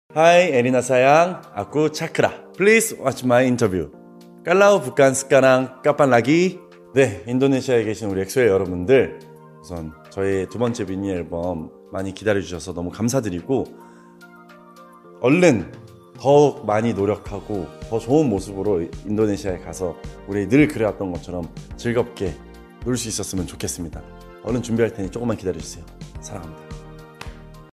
Our exclusive interview with Chanyeol! cr